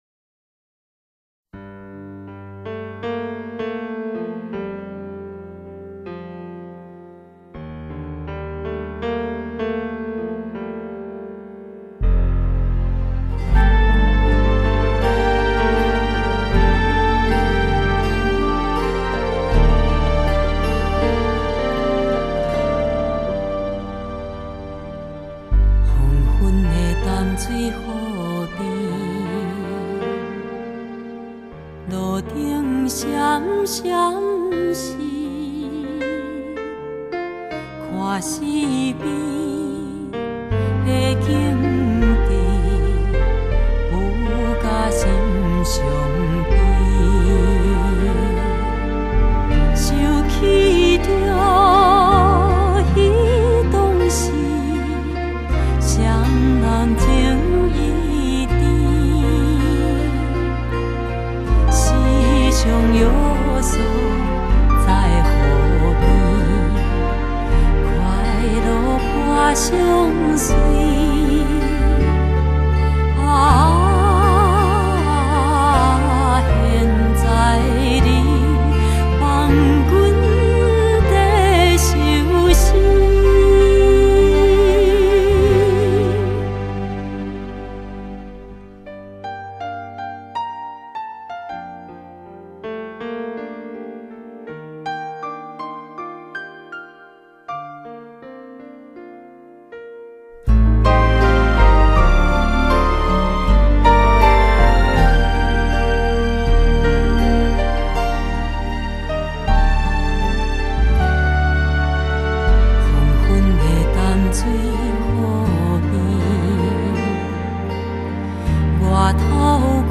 歌壇最佳國台語雙聲帶金嗓 勾勒溫暖懷舊的本土風情畫
擁有低柔慵懶的獨特嗓音
情緒飽滿的唱腔、沈靜的美感